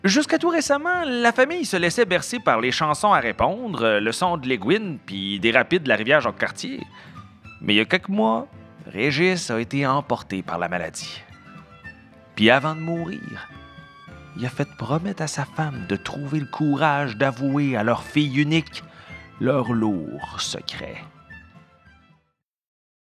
Timbre Médium - Grave
Composition vocale Chaleureuse - Sympathique - Captivante - Souriante - Humoristique
Le Renard au Tambour - Captivant - Conteur - Québécois rural /